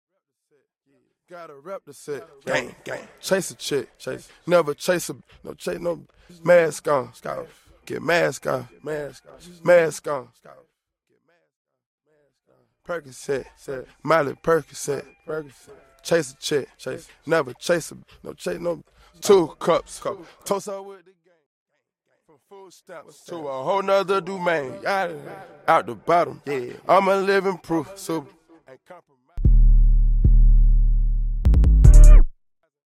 Bassline Sample
Studio Acoustic Background Loop
Studio Bruised Drums Sample
Studio Vocal Riser